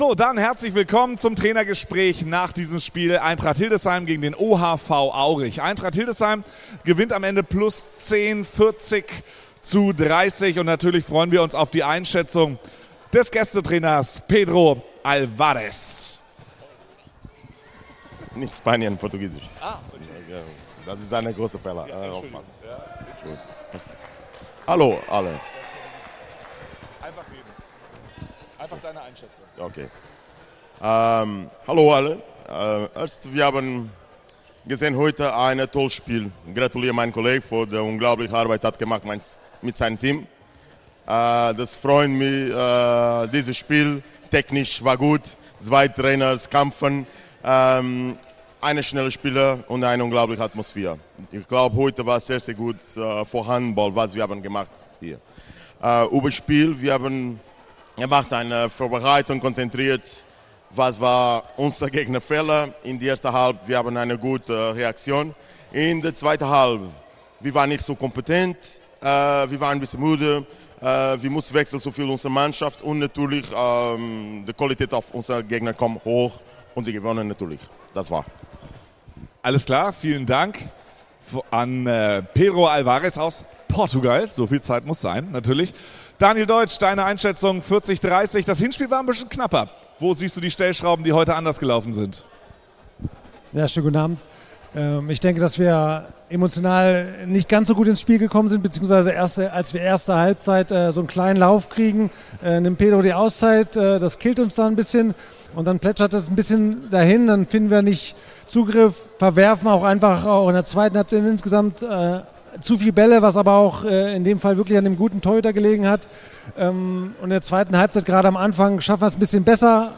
- Pressekonferenz nach dem Spiel gegen den OHV Aurich from Dach überm Kopf - Wohnen in Hildesheim on Podchaser, aired Sunday, 24th March 2024.